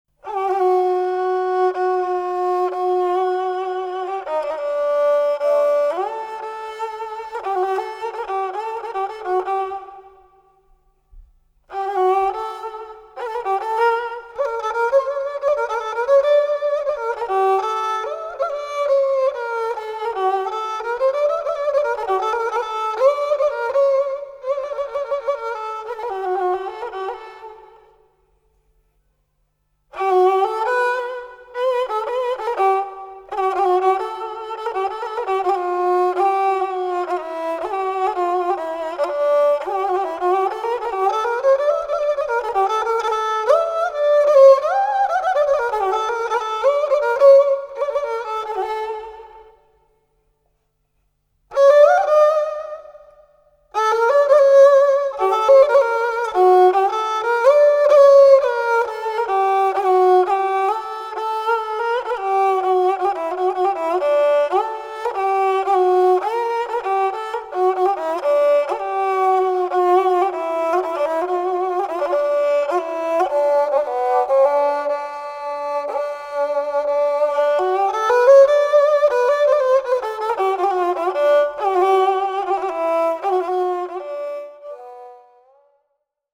Genre: Turkish & Ottoman Classical.
Classical Kemençe